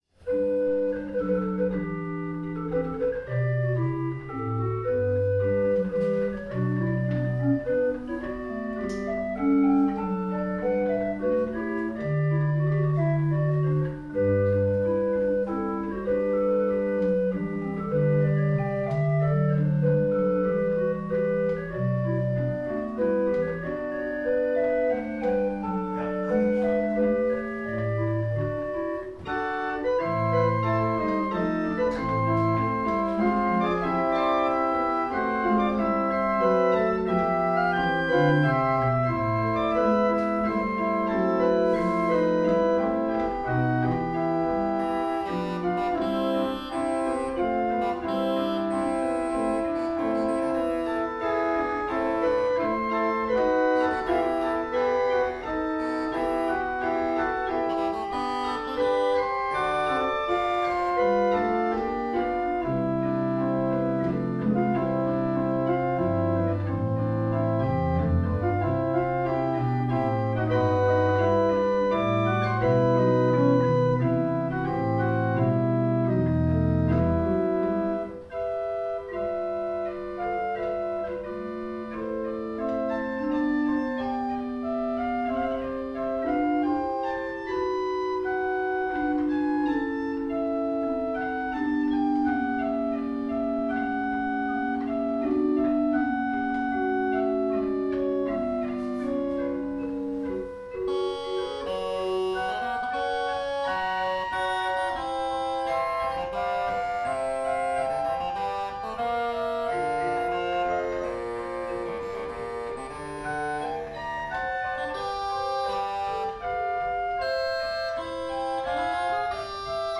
Das ist meine Hausorgel und Truhenorgel
Regal 8' Holzbecher
Stimmung 440 Hz bei 19 °C, ungleichstufig nach Billeter
Wolfgang Seifen sehr für seine Improvisation.